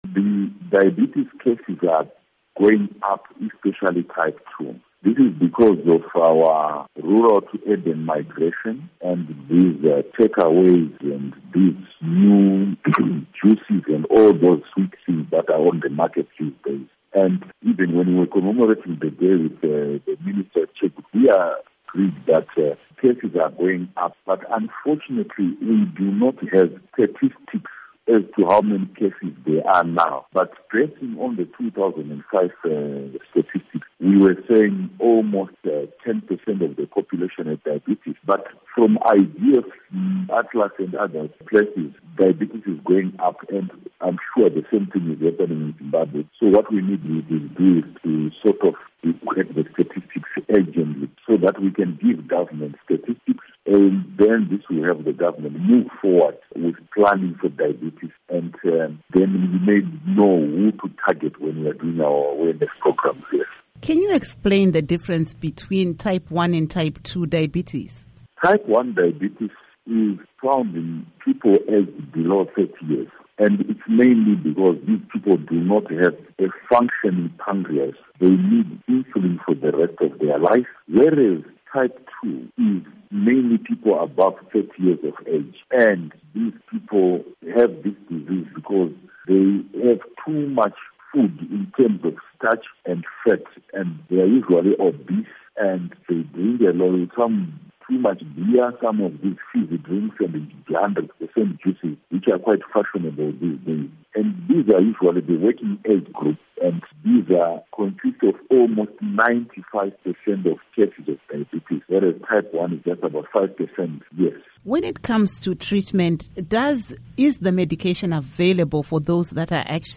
Interview With Dr John Mangwiro